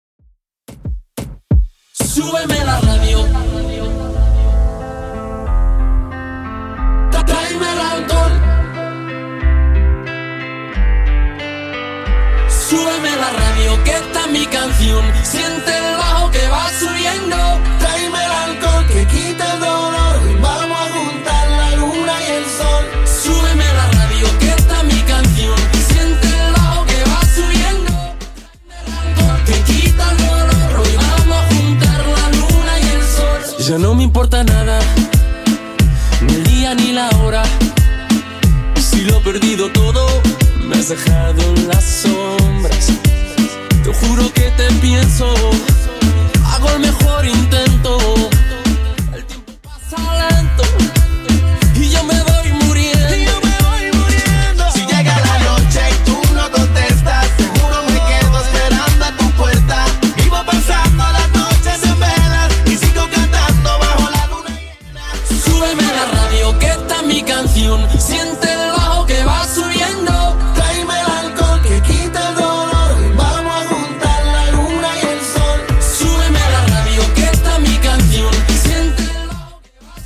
BPM: 91 Time